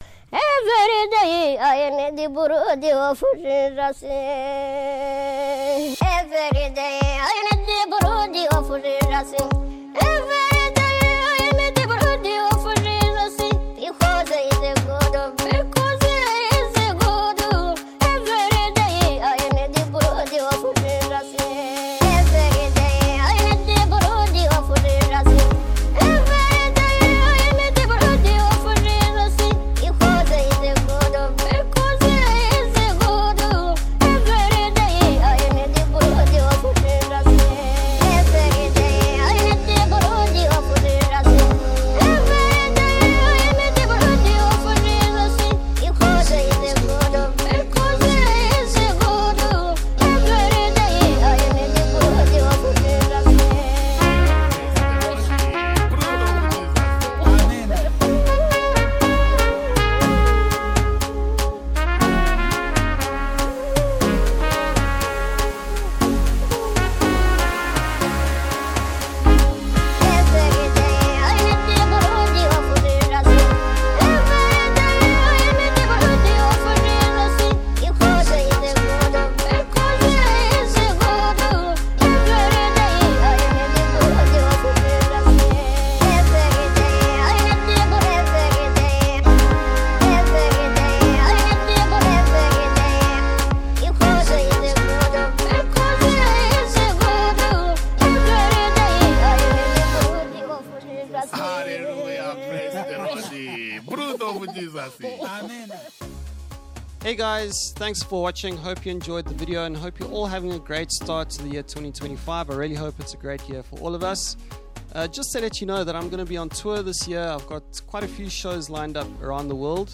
International Gospel Songs
Contemporary Christian music singer